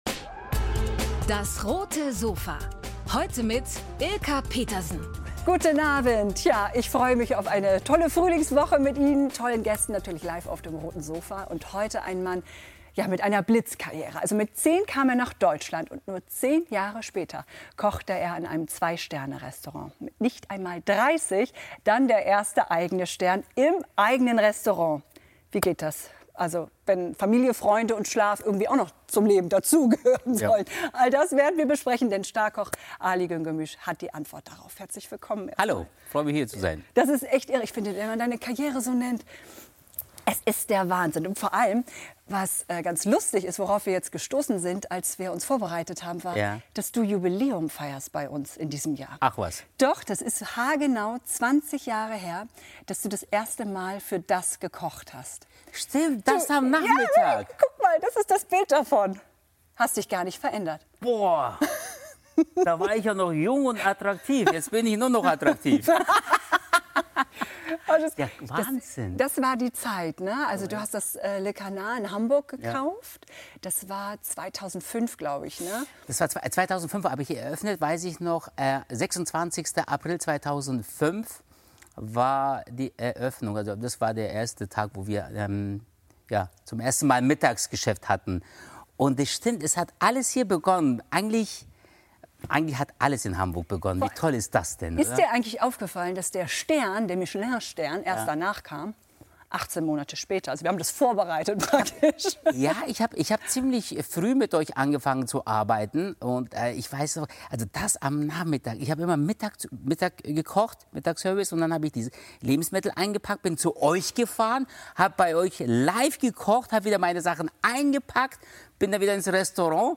Fernsehkoch Ali Güngörmüş zaubert im Studio ~ DAS! - täglich ein Interview Podcast